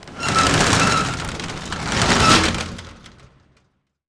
SHATTER 1 -S.WAV